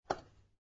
На этой странице собраны звуки микроскопа — от щелчков регулировки до фонового гула при работе.